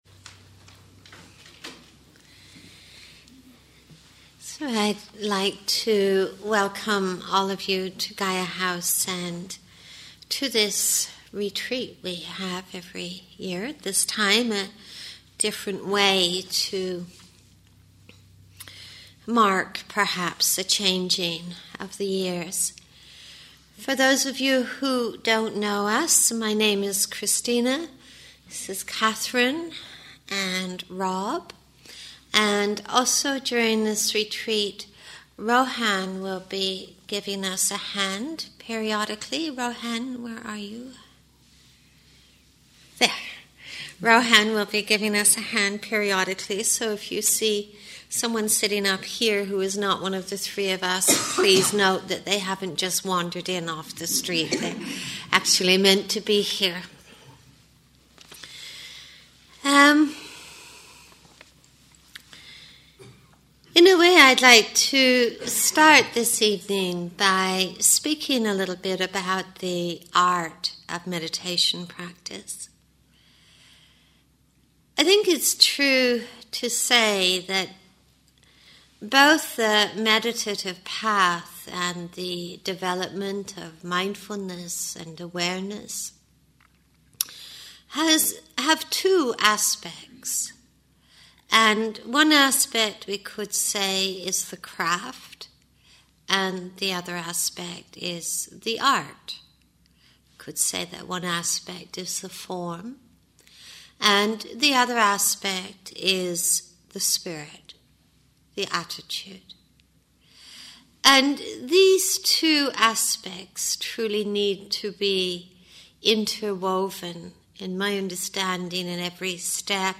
Opening Talk for Stillness and Insight Retreat